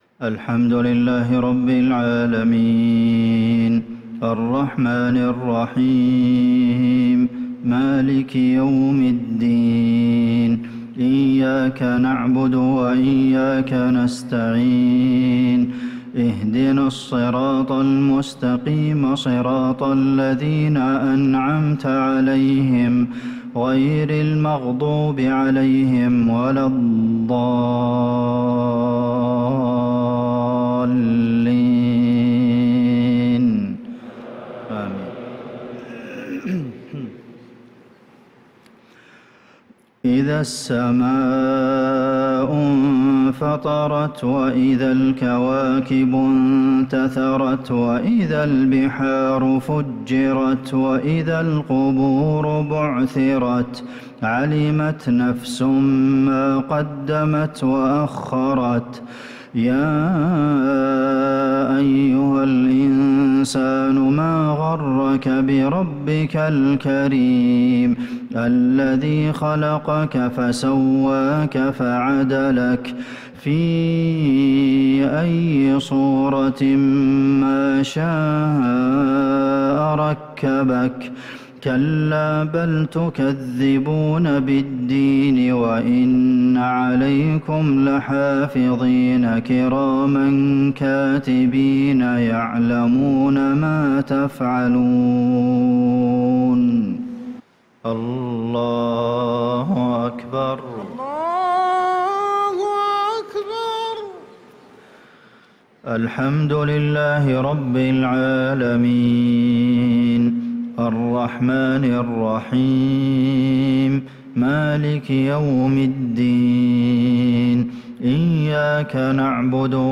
صلاة المغرب للقارئ عبدالمحسن القاسم 24 صفر 1446 هـ
تِلَاوَات الْحَرَمَيْن .